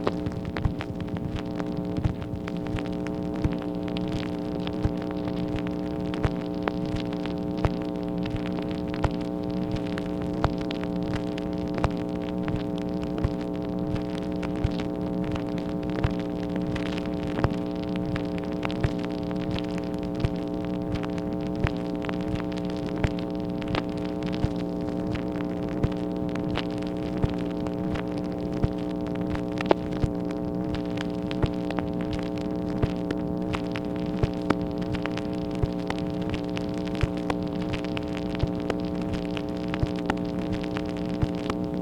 MACHINE NOISE, September 24, 1964
Secret White House Tapes | Lyndon B. Johnson Presidency